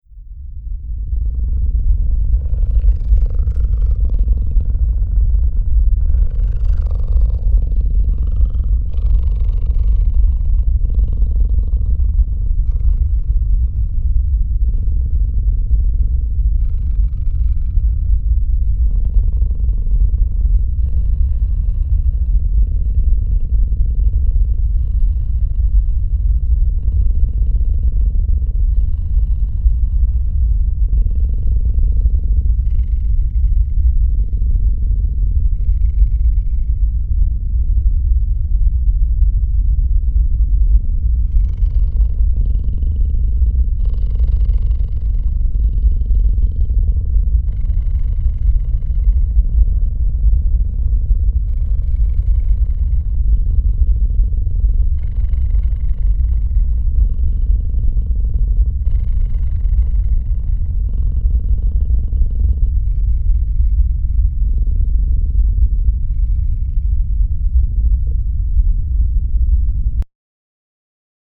Volcanic rock, sound device, 25 x 47cm, 2018
It seems that this colonized volcanic stone breathes, one hears like a snoring, a grunt. The body of the mineral is dug to shelter a sound device diffusing a double sound, that coming from the depths of the earth, of an earthquake, the other the roaring breathing of a panther. These rumblings harmonize to give a kind of telluric snoring.